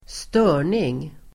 Uttal: [²st'ö:r_ning]